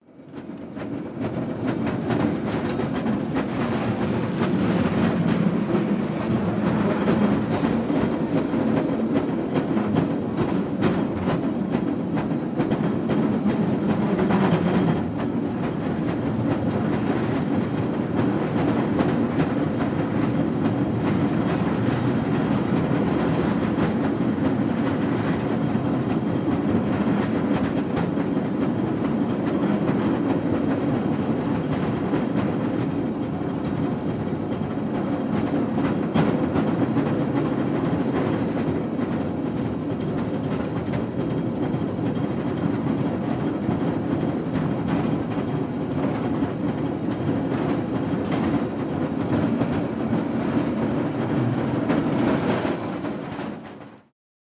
На этой странице собраны звуки трамваев: от ритмичного перестука колес по рельсам до характерных звонков и гула моторов.
Городской трамвай проезжает мимо